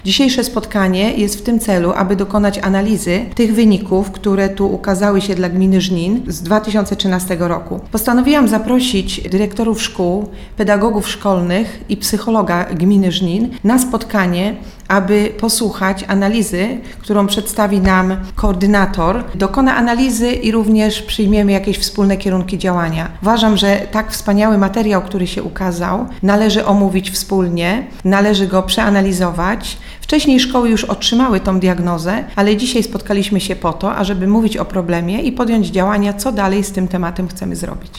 Więcej o spotkaniu przed jego rozpoczęciem powiedziała nam wiceburmistrz Żnina Aleksandra Nowakowska.